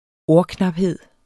Udtale [ ˈoɐ̯ˌknɑbˌheðˀ ]